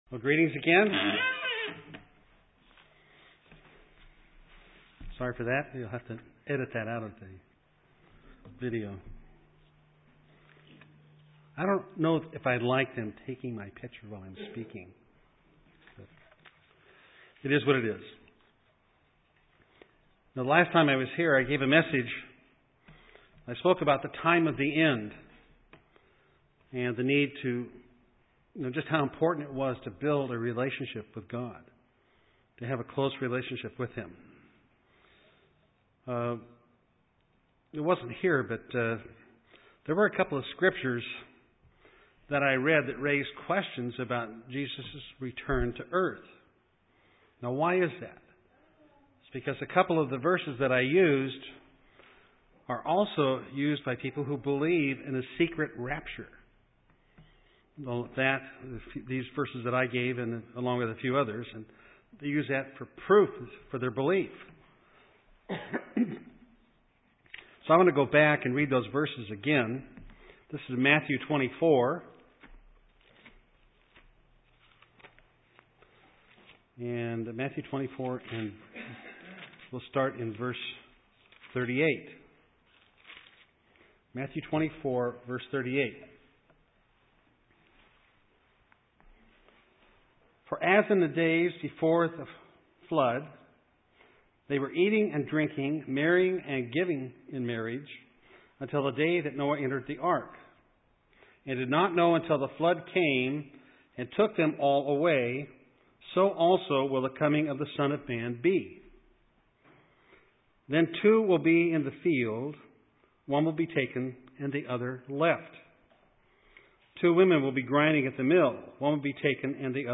Given in Eureka, CA
UCG Sermon Studying the bible?